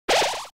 undertale heal sound Meme Sound Effect
undertale heal sound.mp3